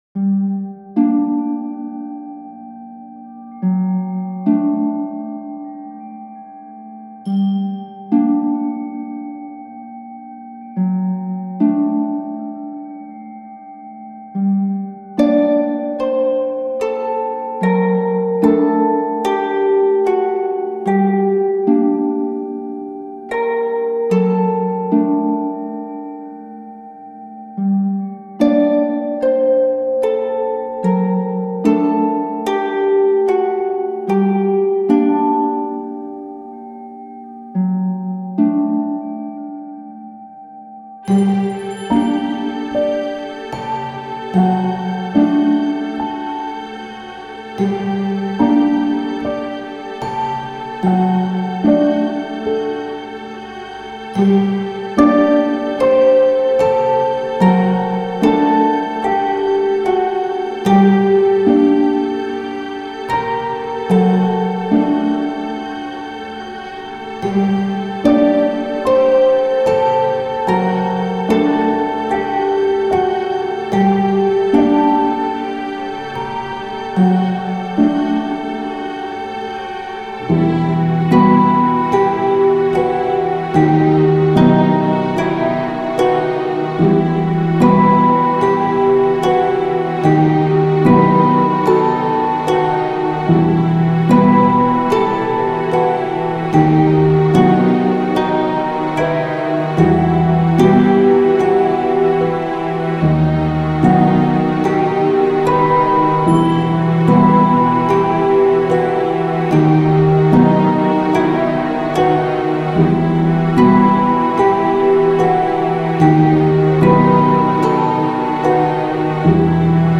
琴を使った暗く怪しげな和風曲です。
テンポ ♩=free